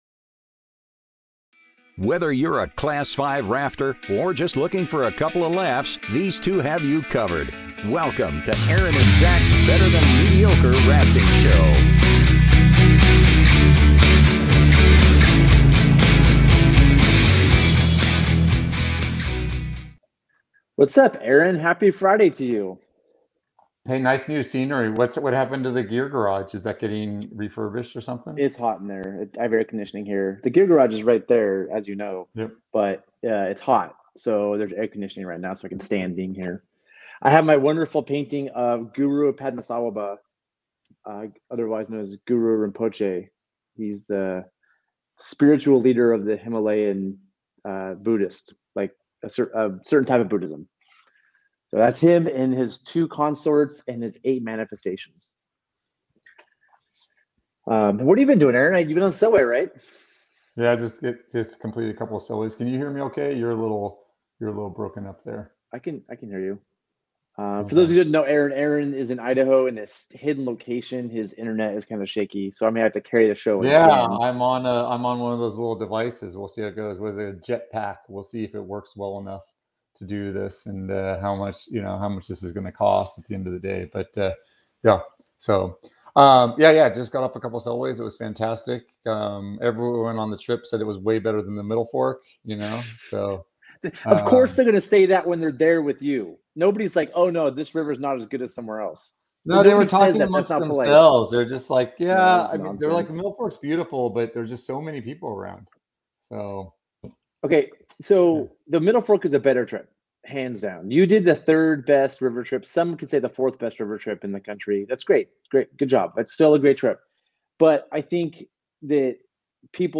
Gear Garage Live Show Pushing and Pulling